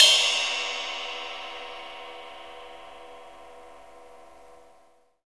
CYM ROCK 0PR.wav